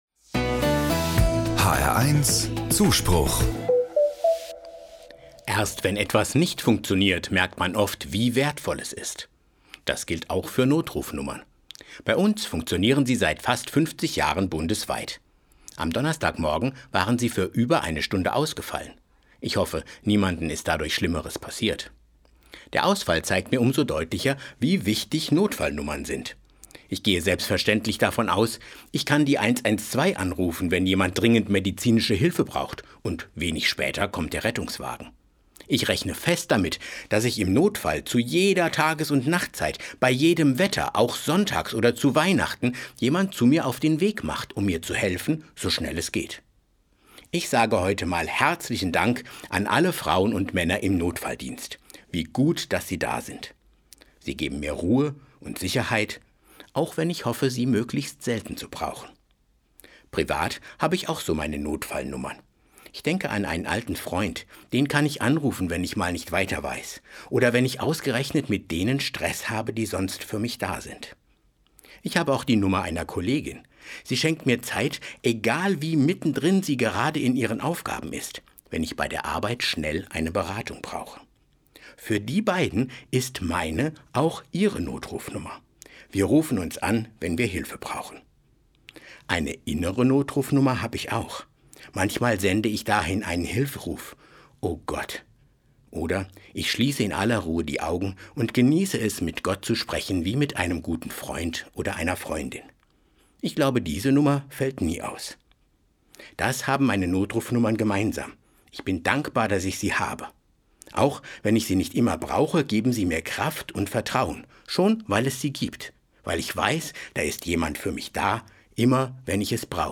Anmoderation: Nicht nur in Hessen, in vielen Teilen Deutschlands war am Donnerstagmorgen zeitweise der Notruf ausgefallen.